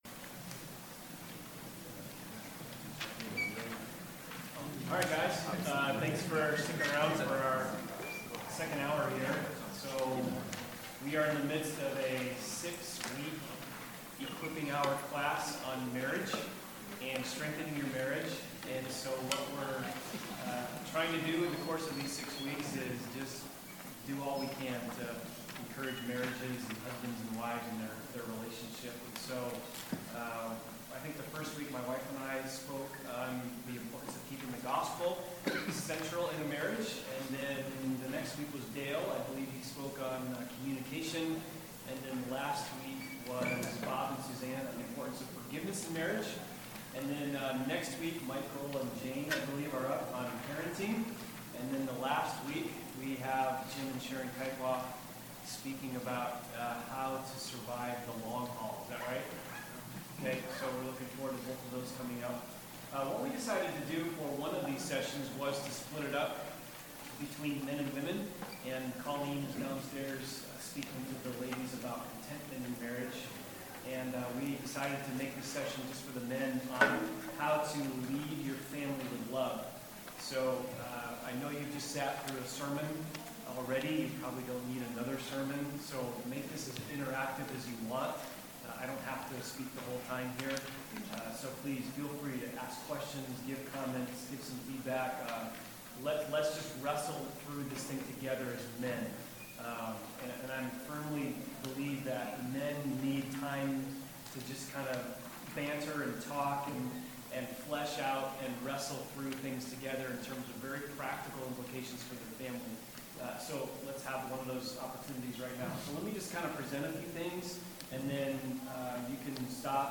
NOTE: For the first 10-15 minutes of the session the volume is quite low.